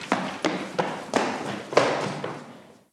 Chico corriendo por un pasillo
Sonidos: Acciones humanas